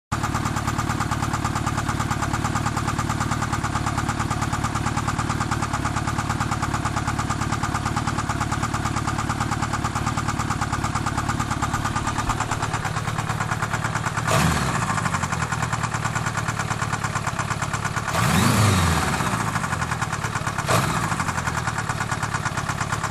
Geräusch: klappern / tackern - Motor
Hört sich immer noch fast wie ein alter Diesel an.
Das Schlagen ist bei höherer Drehzahl unauffälliger, aber nicht weg.
Stimmt, - das Geräusch ist mehr so ein Tackern, wie bei einem Diesel.